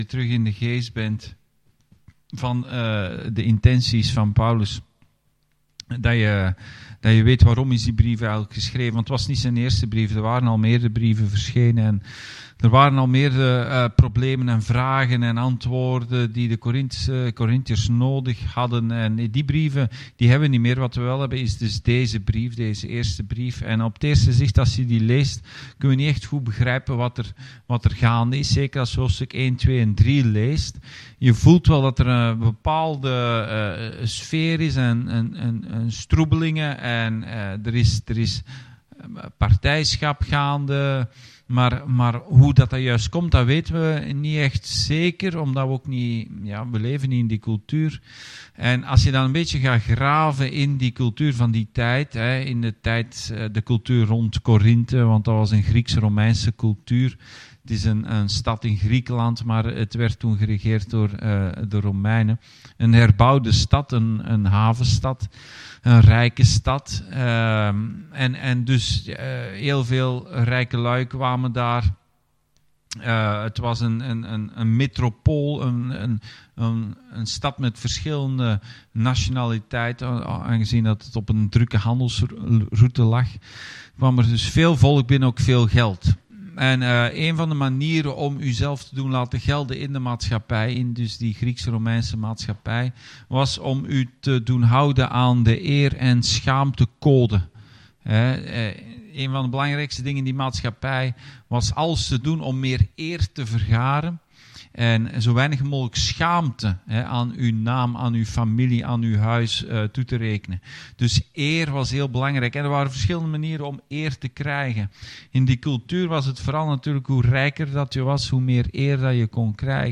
Bijbelstudie: 1 Korintiërs 3